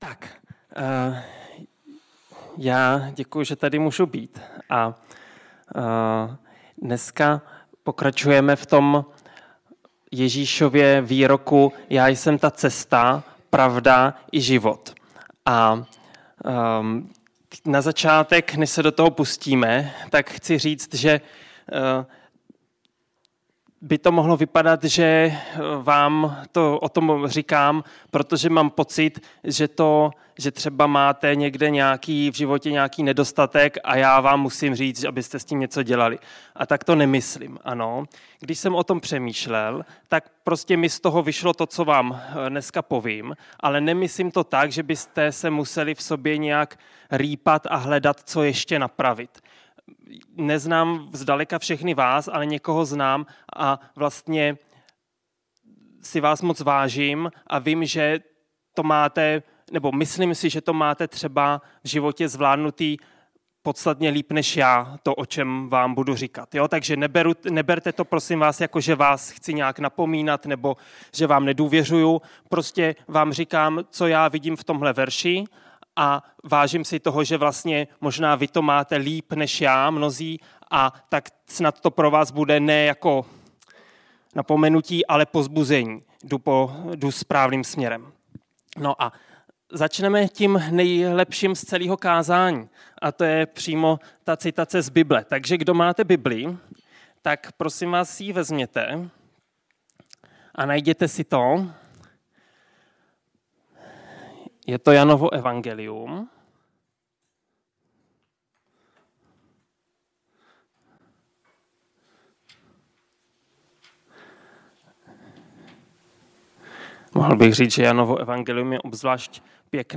Kázání ukazuje, že skrze Ducha Svatého se rodíme k novému životu, zakoušíme Boží lásku a vstupujeme do vztahu s Bohem jako Otcem. Zaměřuje se na kontrast života podle těla a podle Ducha a zdůrazňuje „Ducha synovství“, který nám dává identitu Božích dětí.